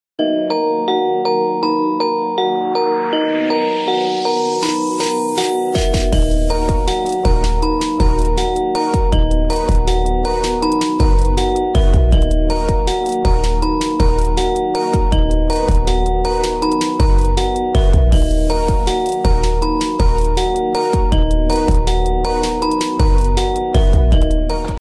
semarak karnaval desa Kalirejo Undaan Kudus